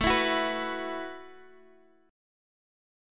A 6th Suspended 2nd